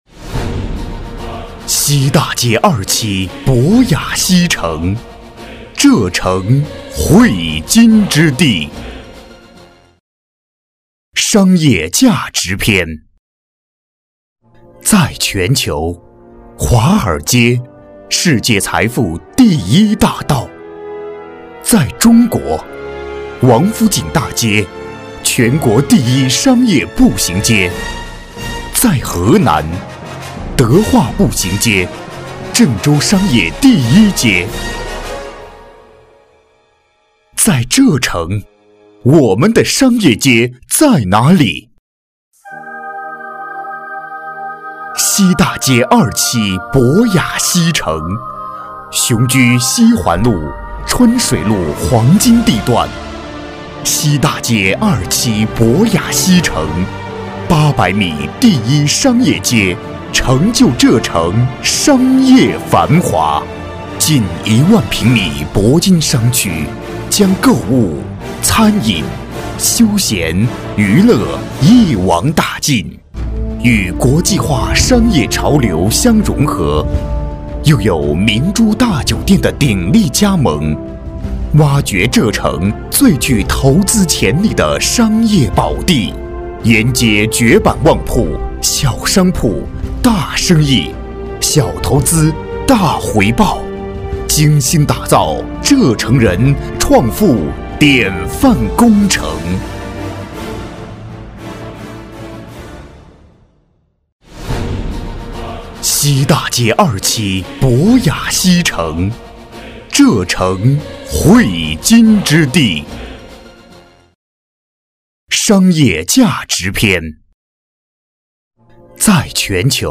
国语中年低沉 、大气浑厚磁性 、沉稳 、娓娓道来 、男纪录片 、80元/分钟男S337 国语 男声 纪录片-探访夏王陵寝-厚重、叙述 低沉|大气浑厚磁性|沉稳|娓娓道来